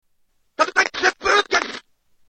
Hurricane Kick Sound